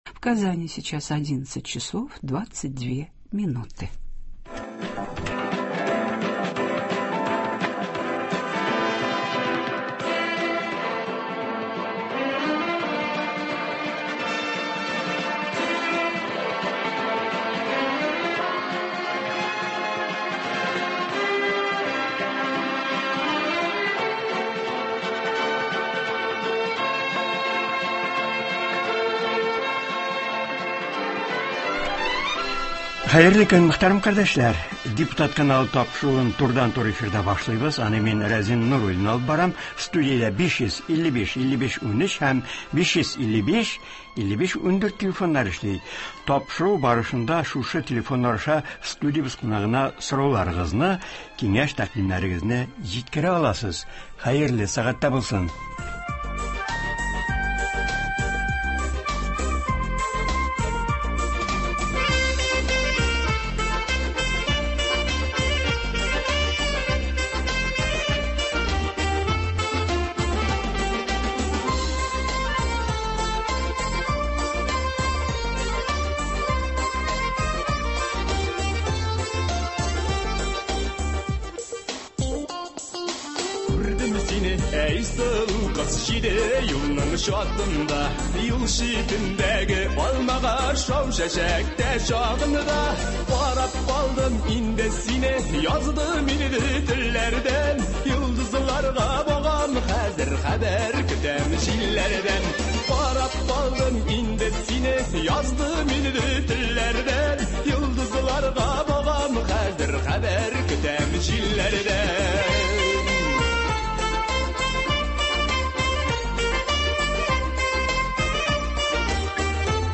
Бу утырышта нинди мәсьәләләр каралды һәм быел көз Россия Дәүләт Думасына сайлаулар үткәрелү уңаеннан каникул чорына нинди бурычлар куела? Республикабызның әдәбият-сәнгать әһелләре Туган телләр һәм халыклар бердәмлеге елын ничек үткәрә? Болар хакында турыдан-туры эфирда Татарстан республикасы Дәүләт Советы депутаты, Татарстан Язучылар берлеге рәисе Ркаил Зәйдулла сөйләячәк һәм тыңлаучылар сорауларына җавап бирәчәк.